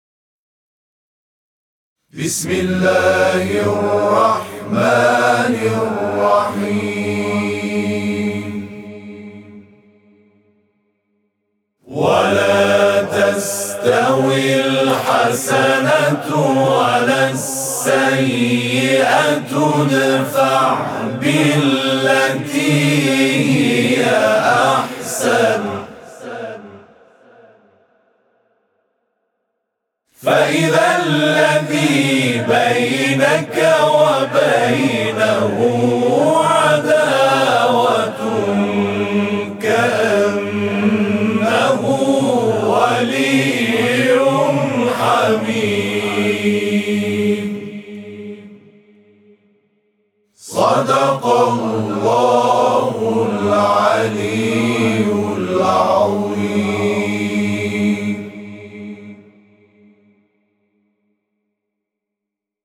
صوت همخوانی آیه 34 سوره فصلت از سوی گروه تواشیح «محمد رسول‌الله(ص)»